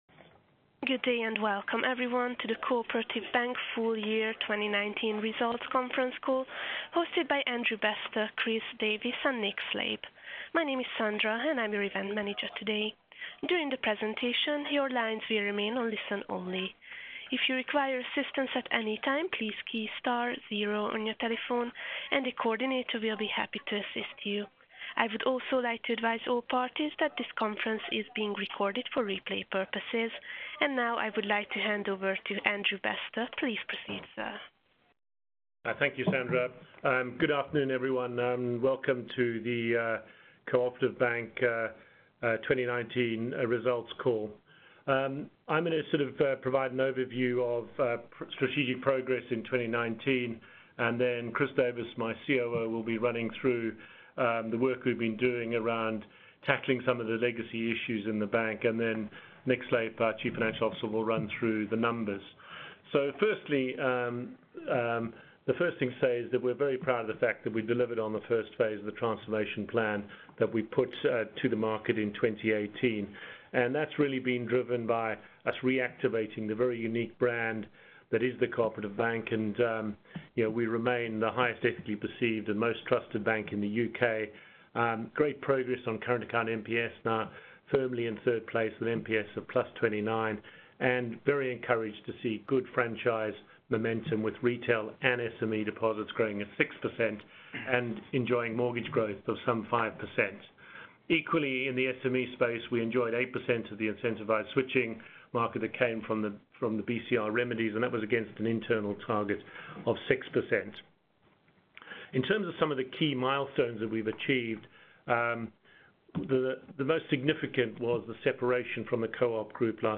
Co-operative-Bank-2019-results-call-recording.wma